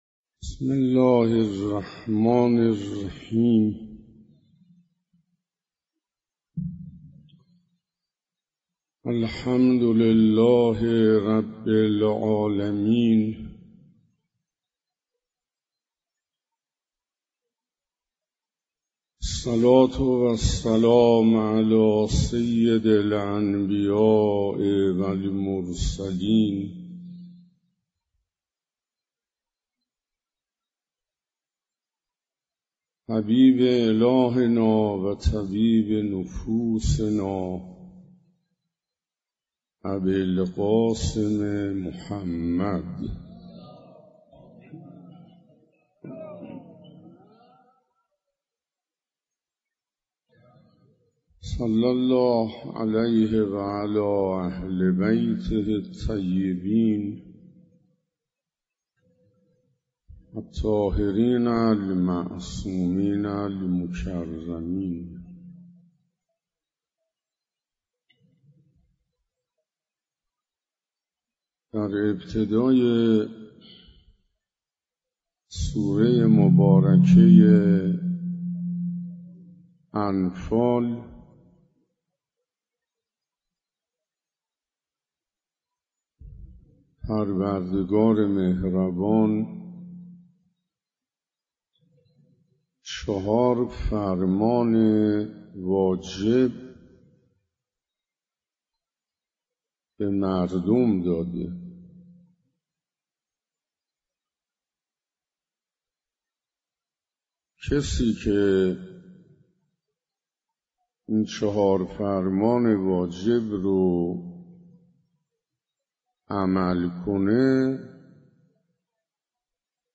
سخنرانی حجت الاسلام انصاریان با موضوع اهلبیت (ع) و خصوصیات مومنان و شیعیان واقعی - 2 جلسه